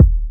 808Kick11.wav